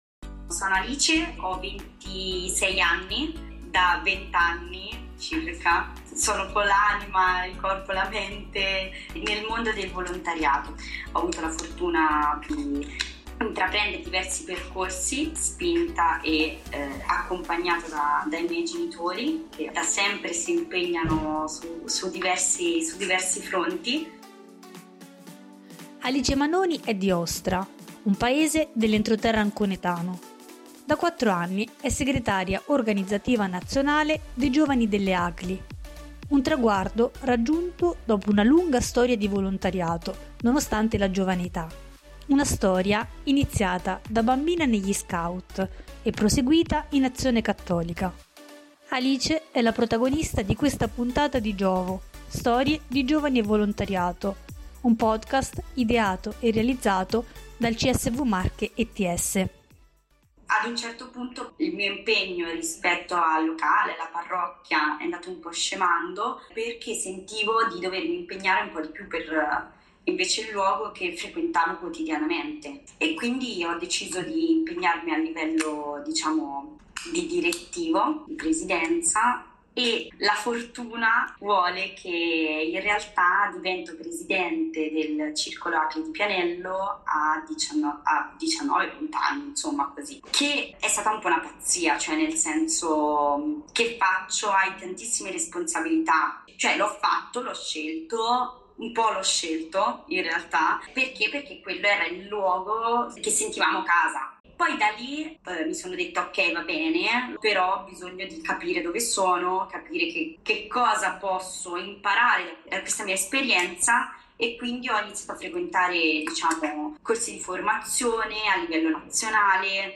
In questa intervista ci racconta la sua già lunga esperienza nel mondo del volontariato